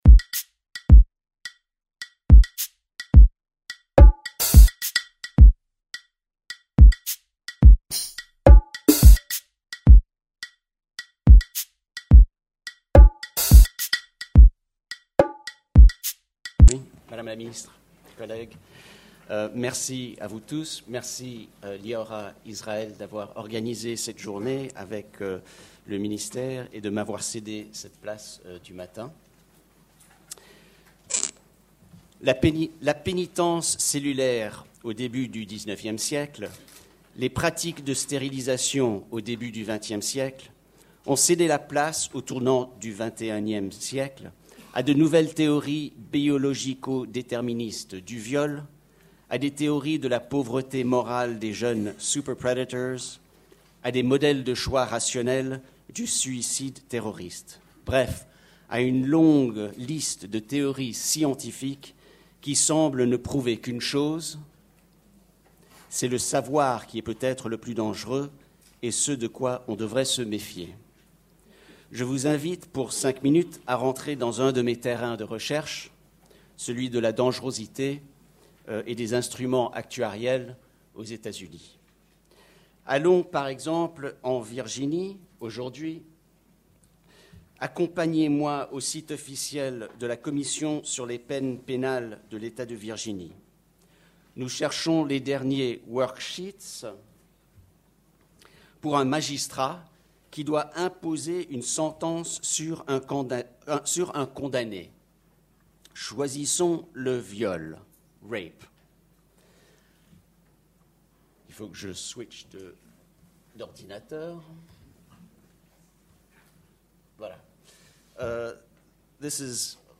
Journée organisée par le ministère de la Justice et l'EHESS